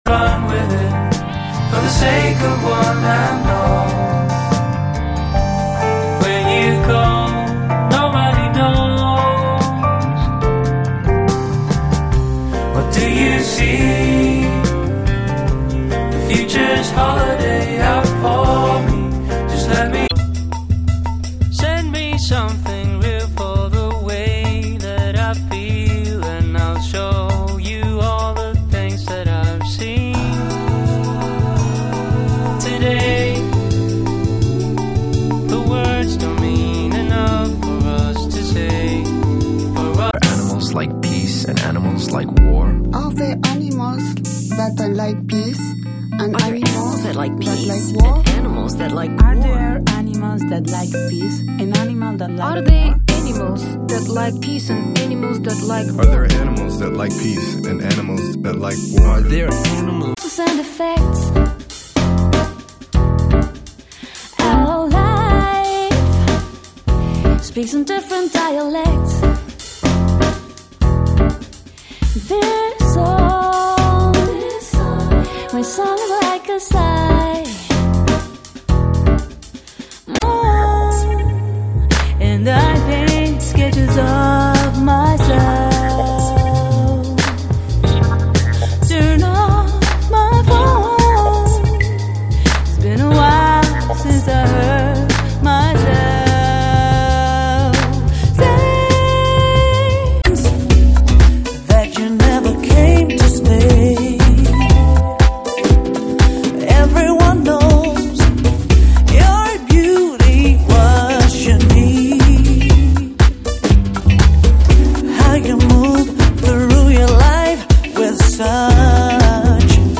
A hard sound. Groovy in a nujazz way.
MP3 sound bite (3.1MB). 20 seconds from each song.